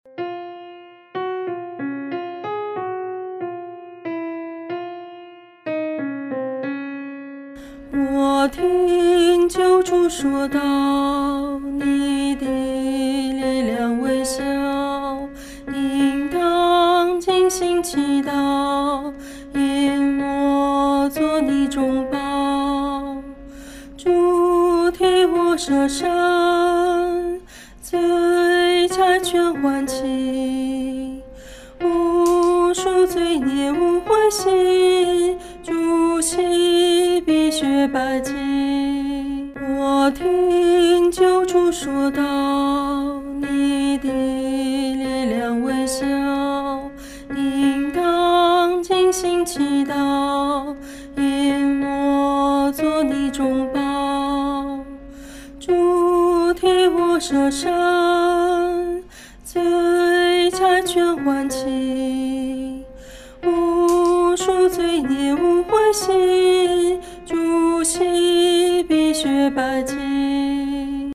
合唱
女高 下载